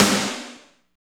50.06 SNR.wav